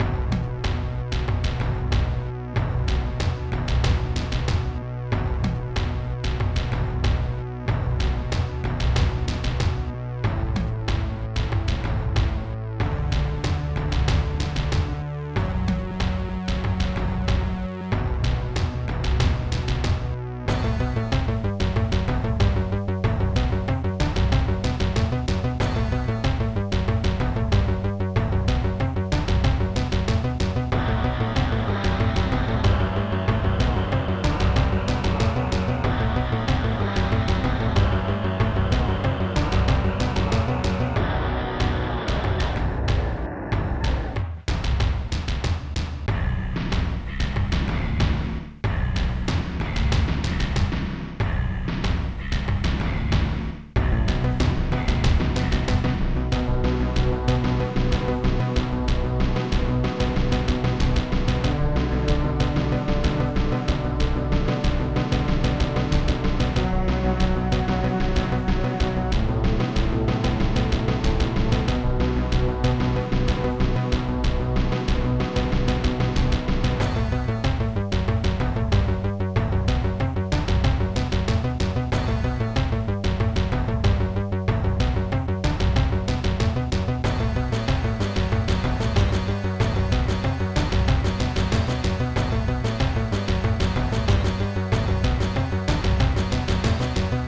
Technobass